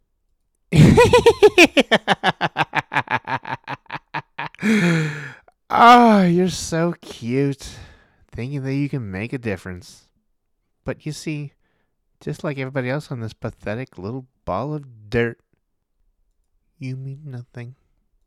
Male
Wild and Jokeresque psychotic mad-scientist vibes.
Video Games
Crazy Psychotic Sinister
Words that describe my voice are textured, deep, authoritative.
0326Crazy_Psychotic_Menacing_Villain.mp3